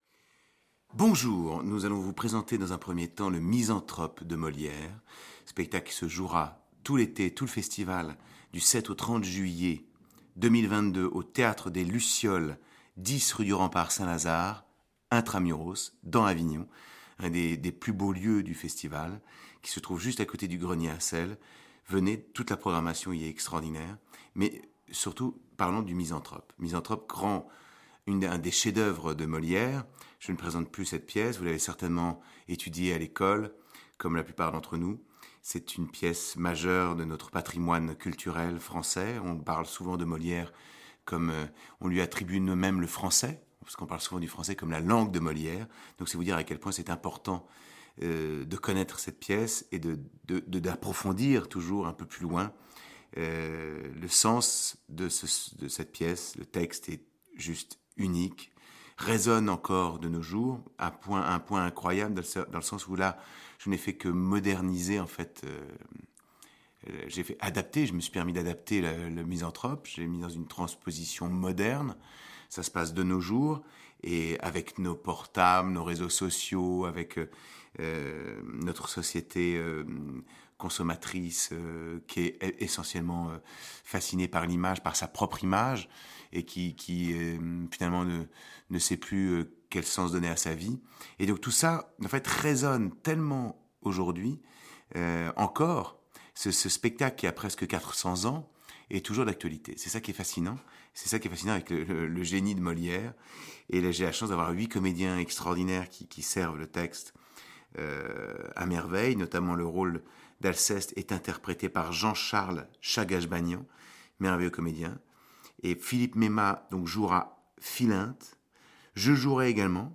c'est au micro de Fréquence Mistral Avignon.